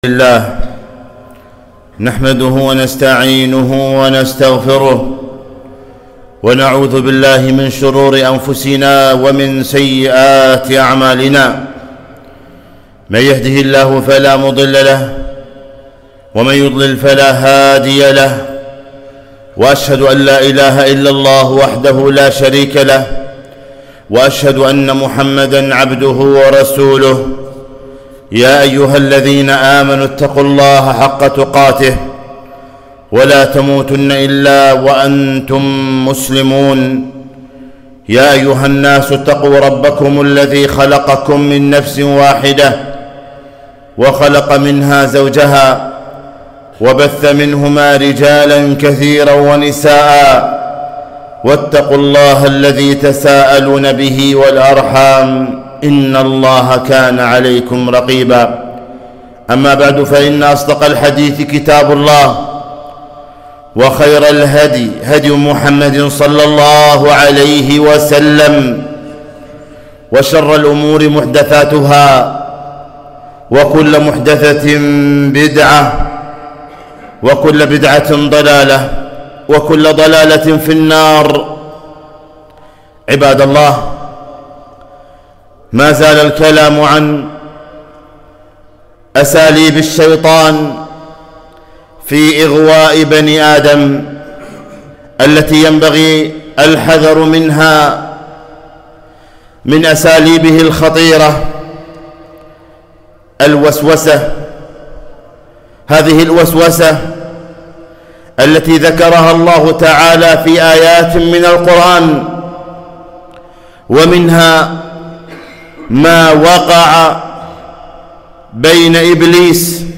خطبة - الحذر من وسوسة الشيطان